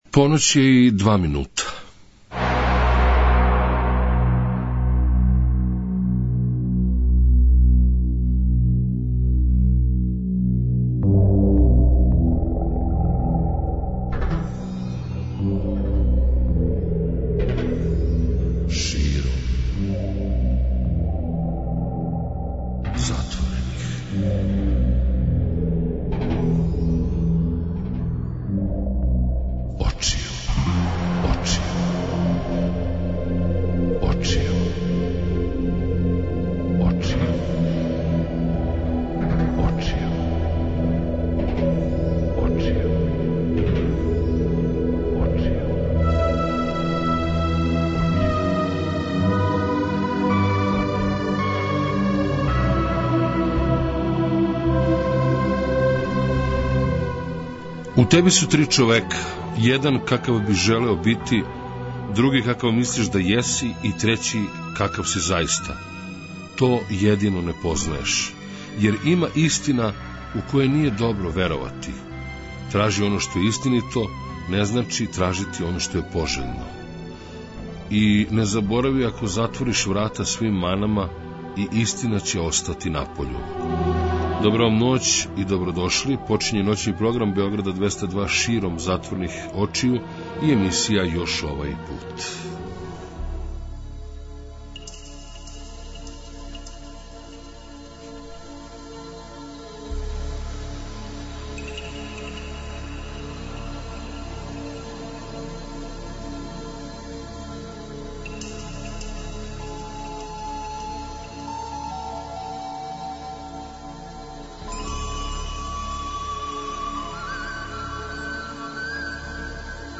преузми : 56.46 MB Широм затворених очију Autor: Београд 202 Ноћни програм Београда 202 [ детаљније ] Све епизоде серијала Београд 202 Устанак Блузологија Свака песма носи своју причу Летње кулирање Осамдесете заувек!